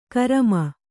♪ karama